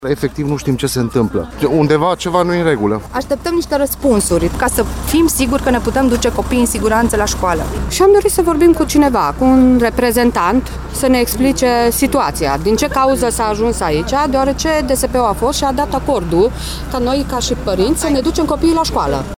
Aproximativ 15 părinți ai elevilor de la Liceul German din Arad au protestat, aseară, în fața școlii, după ce alţi 18 elevi au ajuns la spital, la zece zile de la efectuarea acțiuni de dezinsecție, dezinfecție și deratizare.
02-VOXURI-PARINTI.mp3